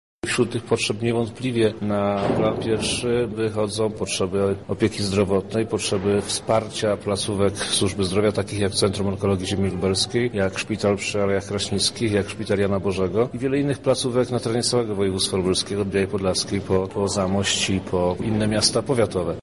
Zapytaliśmy jednego z członków zespołu posła Przemysława Czarnka, jakie są według niego najpilniejsze potrzeby naszego województwa: